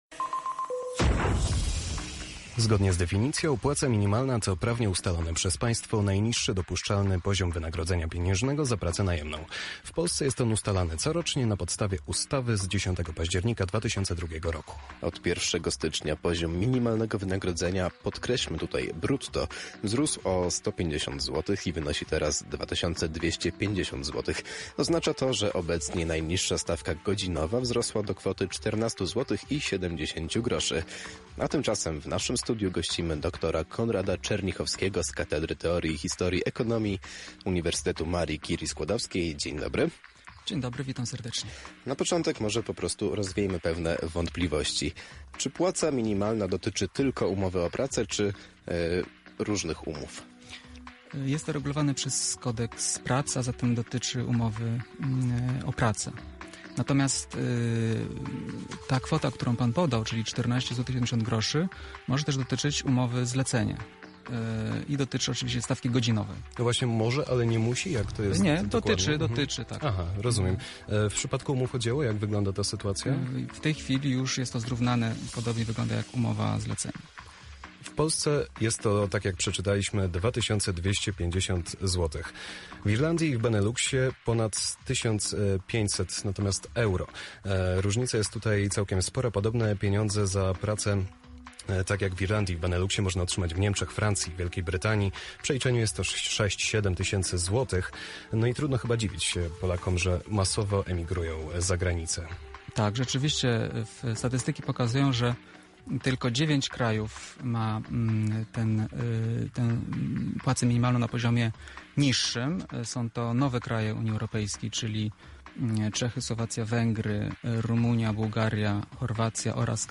Rozmowa-o-płacy-minimalnej-1.mp3